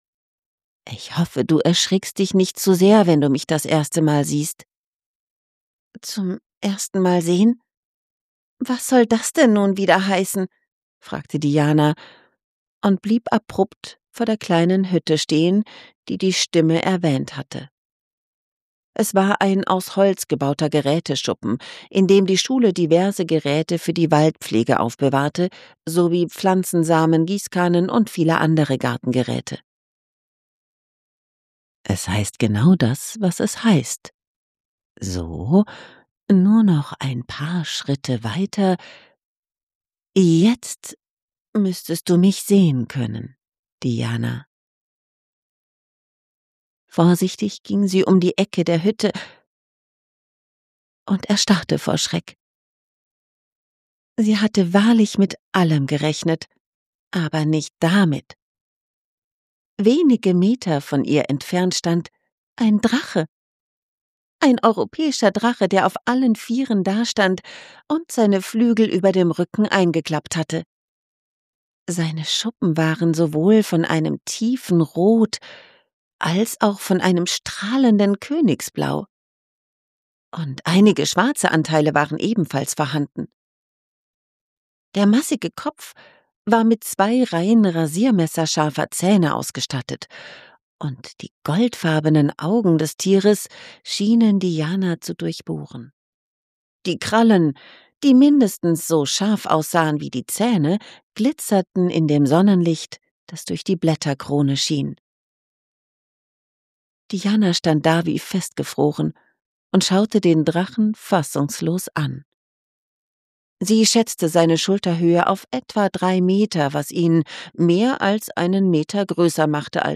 hell, fein, zart, dunkel, sonor, souverän
Mittel minus (25-45)
Fantasy
Audiobook (Hörbuch)